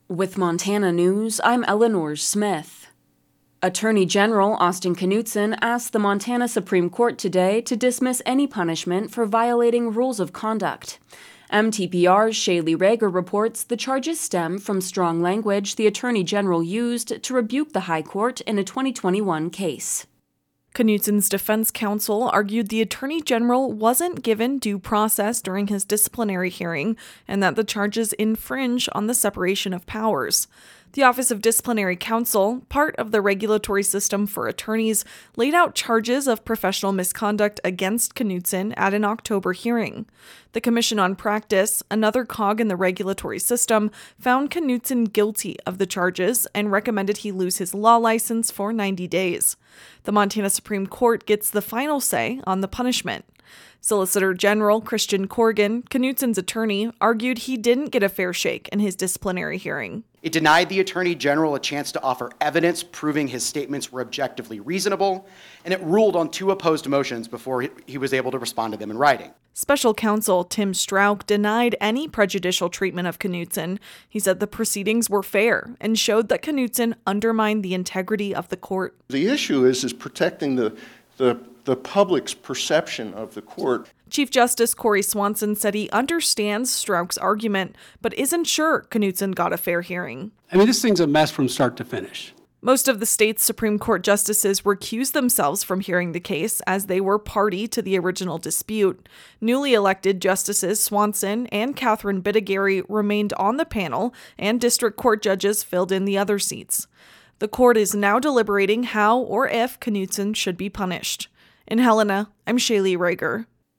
Genres: Daily News, News, Politics